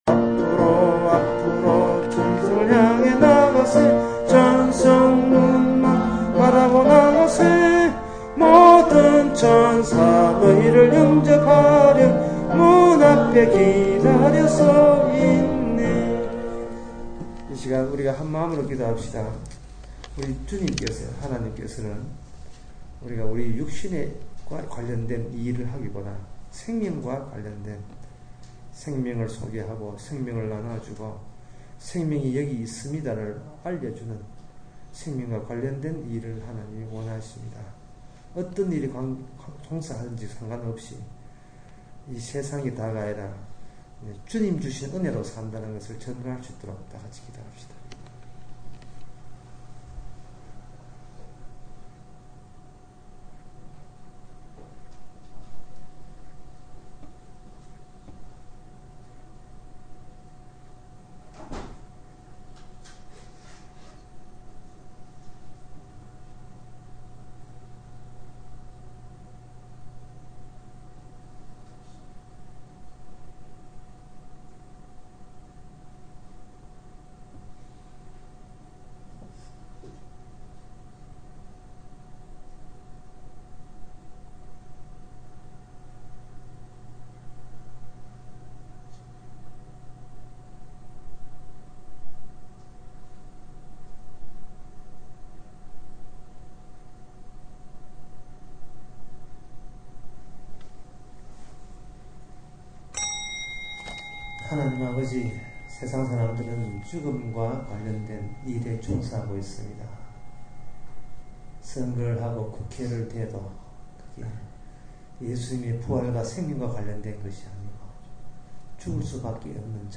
구약 설교, 강의